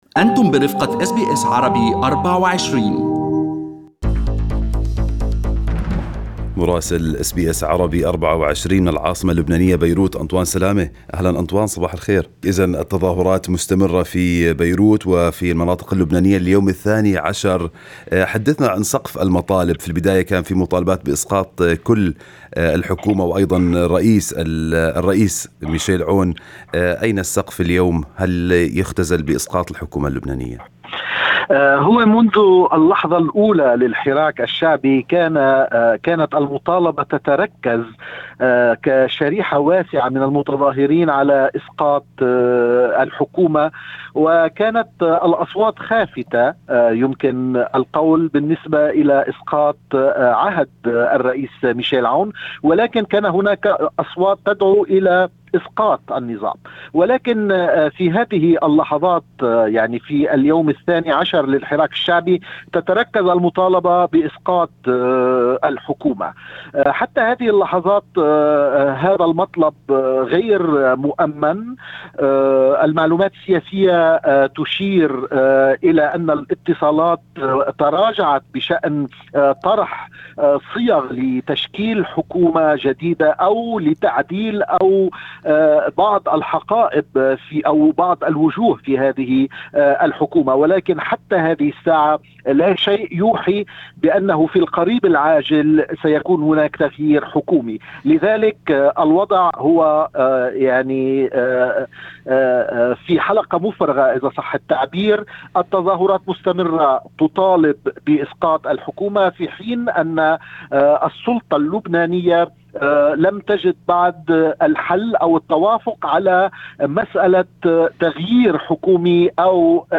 التقرير الكامل